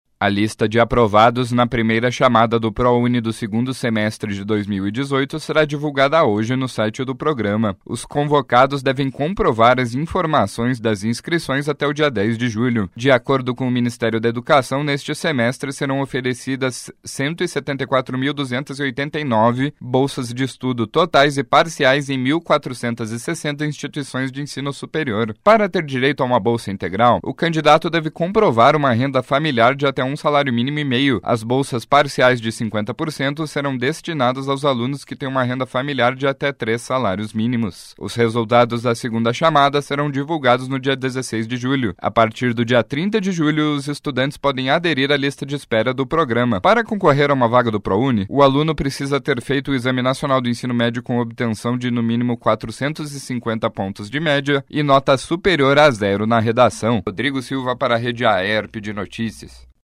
02.07 – BOLETIM SEM TRILHA – Lista de aprovados na primeira chamada do Prouni do segundo semestre de 2018 será divulgada hoje